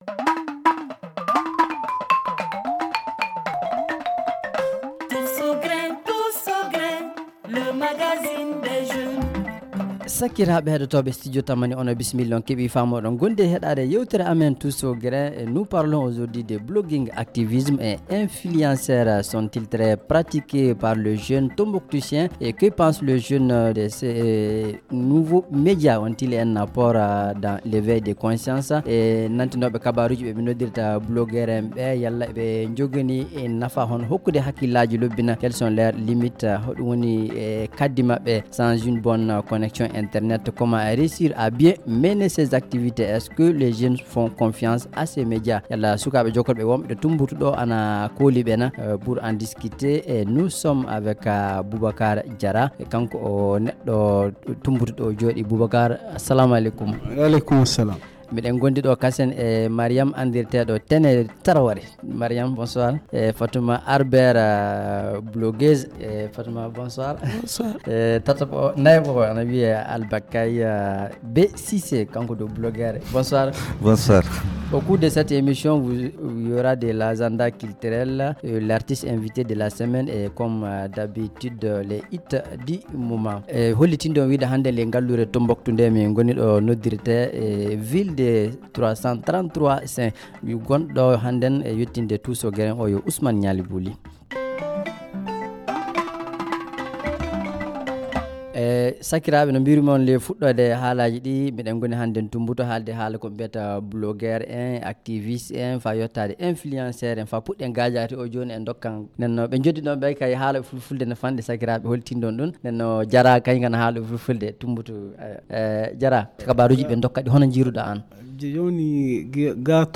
Le Tous au grin de cette semaine pose le débat et c’est délocalisé à Tombouctou.